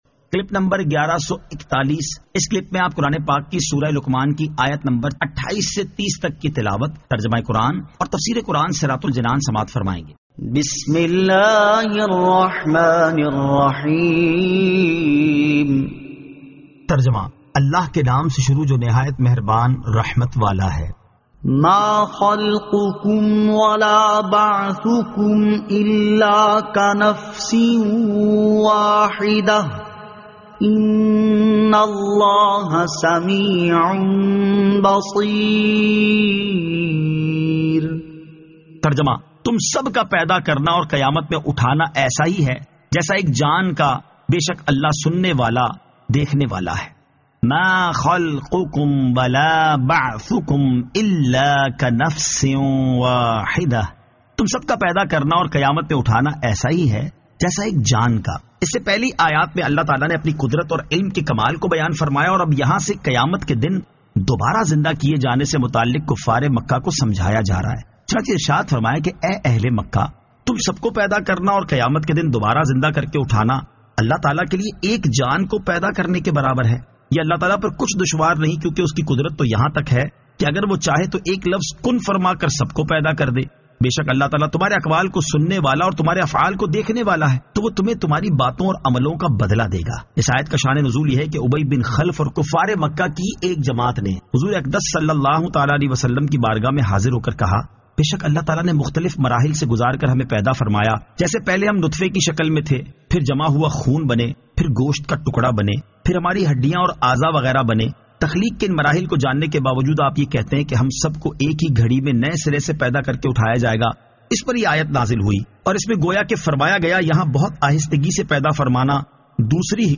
Surah Luqman 28 To 30 Tilawat , Tarjama , Tafseer
2023 MP3 MP4 MP4 Share سُوَّرۃُ لُقٗمَان آیت 28 تا 30 تلاوت ، ترجمہ ، تفسیر ۔